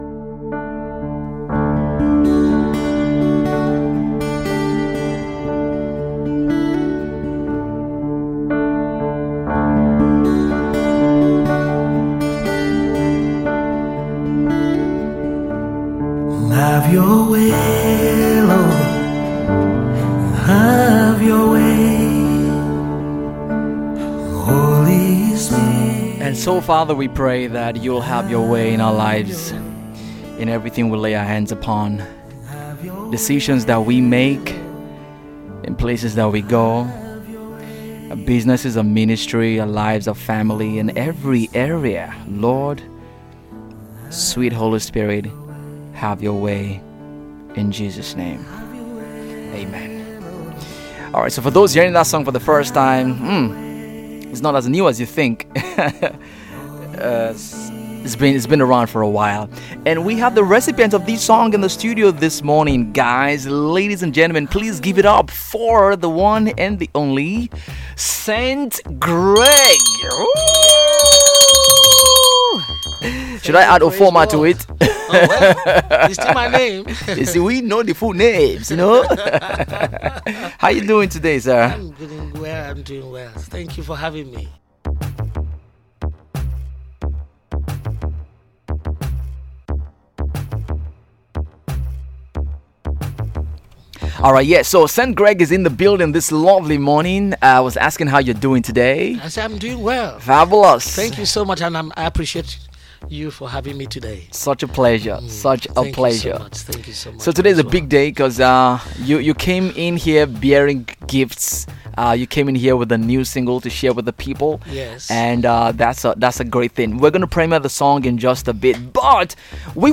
Special Project / Interview